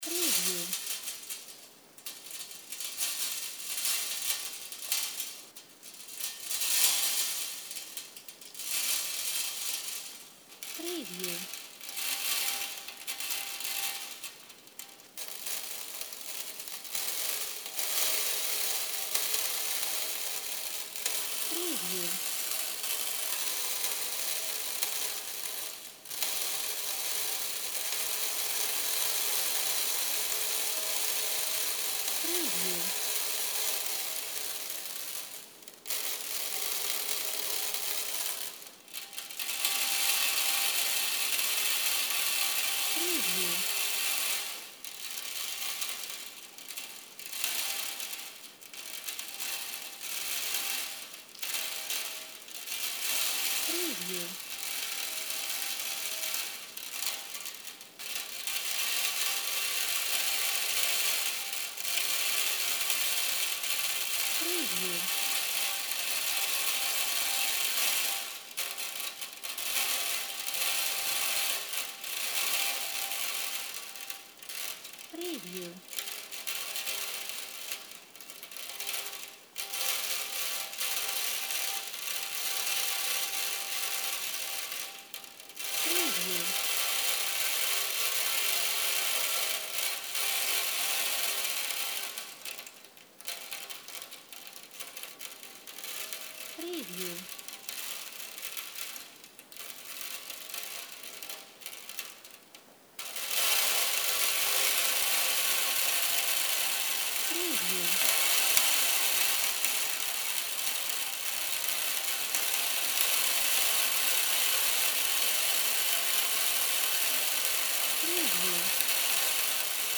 Spilling Sugar on metal surface
A lot of Sugar is being poured on a metal plate. It falls on a metal surface in different tempos, different grist, what makes some nice and a kind of atmospheric sound.
SpillingSugar-preview.mp3